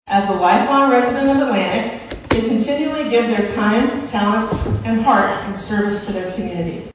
(Atlantic) – Each year, the Atlantic Area Chamber of Commerce honors an outstanding community leader with its highest accolade, the Distinguished Service Award, presented at the Annual Awards Banquet on Friday evening.
The Annual Atlantic Chamber of Commerce Awards Banquet was held at The Venue in downtown Atlantic.